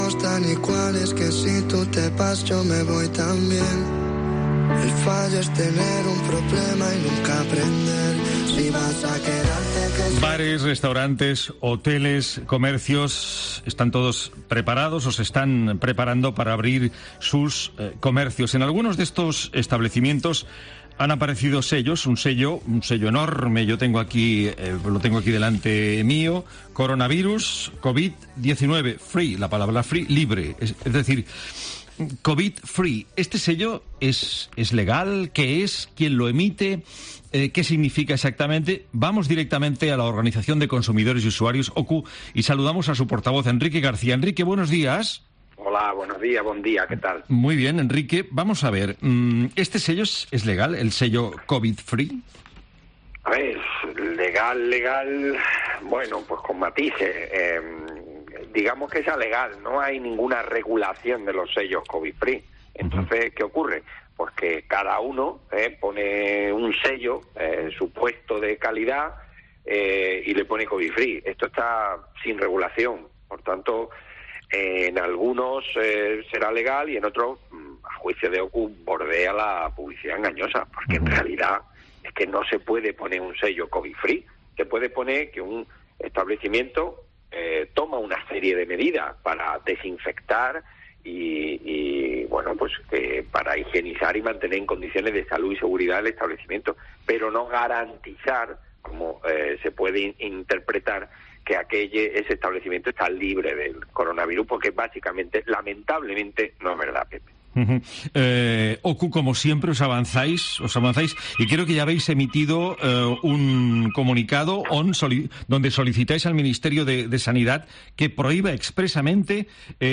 “OCU ha pedido al Ministerio de Sanidad que prohíba los sellos "Covid free"